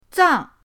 zang4.mp3